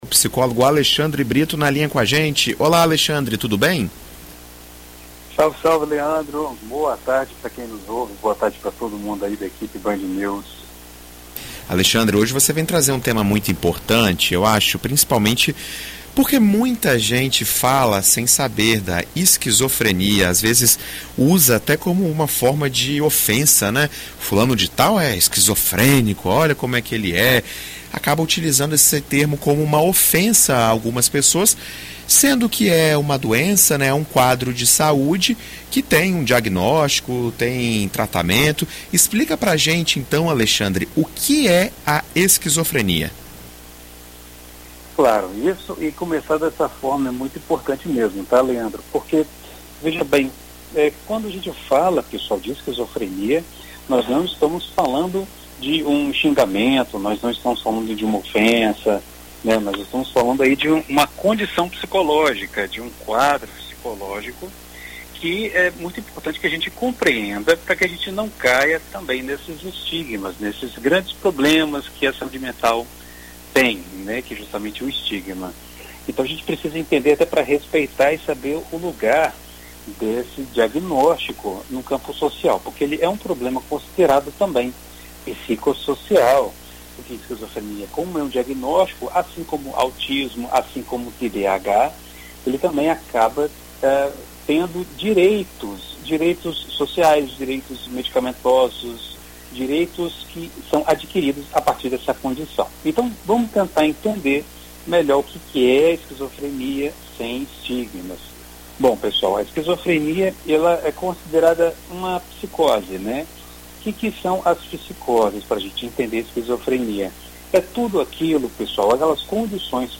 Em entrevista à BandNews FM ES nesta segunda-feira